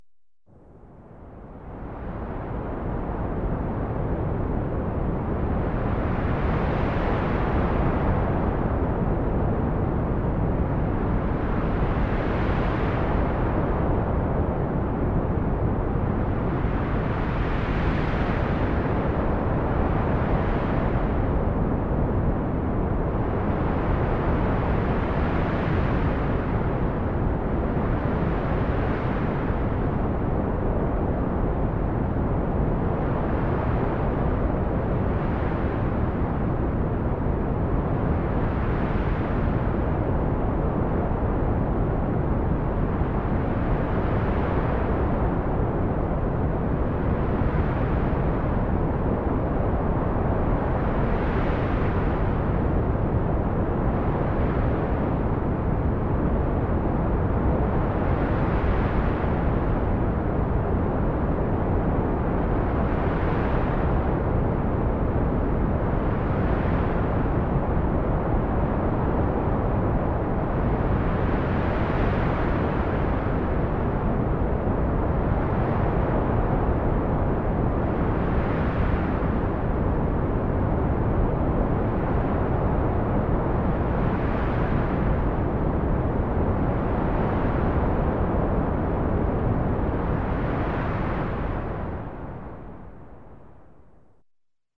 Ветер звуки скачать, слушать онлайн ✔в хорошем качестве